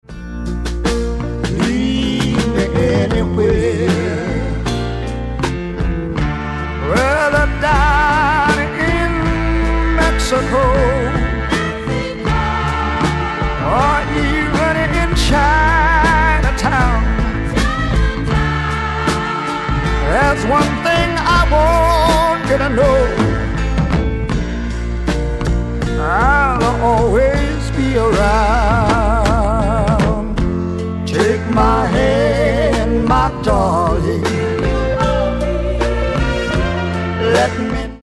ソリッドなリズム・セクションとリズミカルなホーンをフィーチャーしたスワンプ・ロック傑作
ブルー・アイド・ソウル感溢れるふたりのヴォーカルのコンビネーションも最高の一枚！